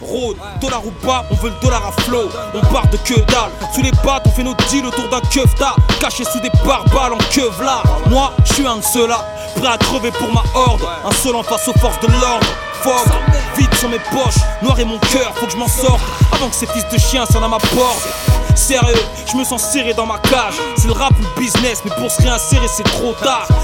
• Качество: 128, Stereo
речитатив
французский рэп